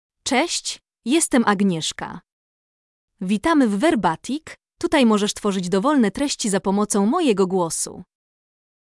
AgnieszkaFemale Polish AI voice
Agnieszka is a female AI voice for Polish (Poland).
Voice sample
Female
Agnieszka delivers clear pronunciation with authentic Poland Polish intonation, making your content sound professionally produced.